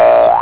sproing.au